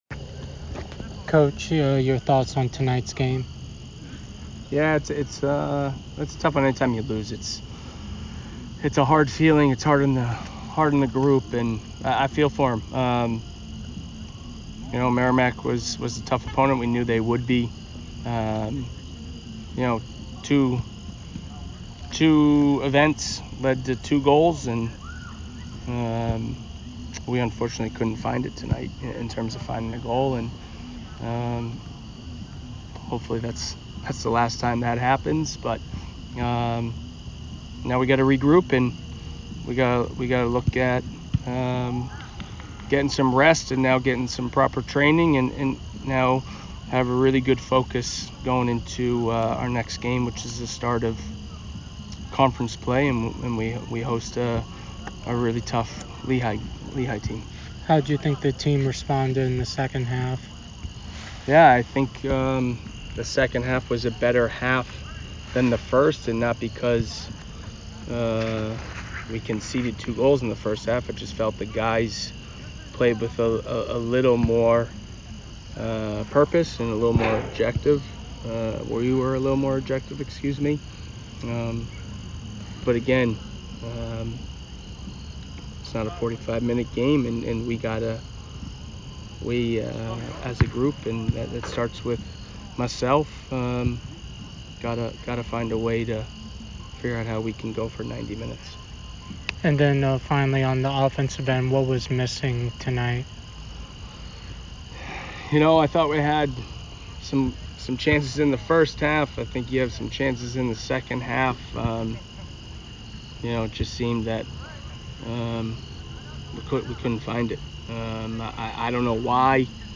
Merrimack Postgame Interview